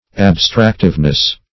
abstractiveness - definition of abstractiveness - synonyms, pronunciation, spelling from Free Dictionary
Search Result for " abstractiveness" : The Collaborative International Dictionary of English v.0.48: Abstractiveness \Ab*strac"tive*ness\, n. The quality of being abstractive; abstractive property.